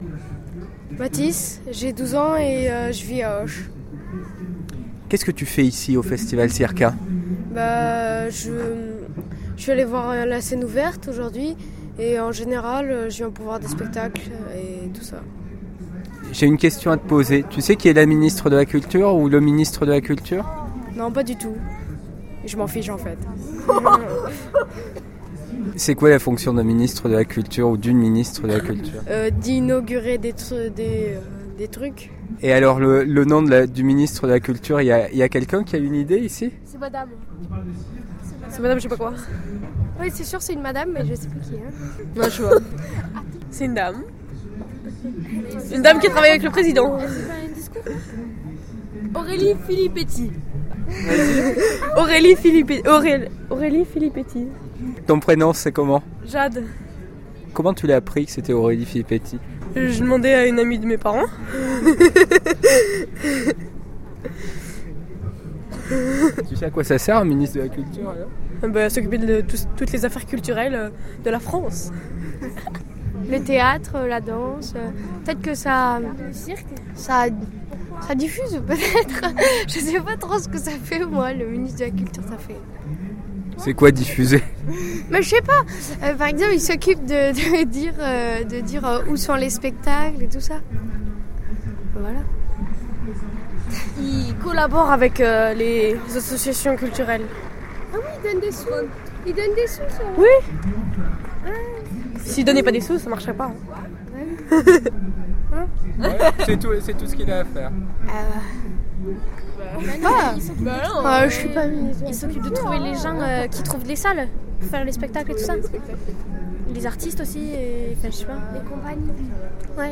Étiqueté 2012 autre reportage Laisser un commentaire Annuler la réponse Votre adresse e-mail ne sera pas publiée.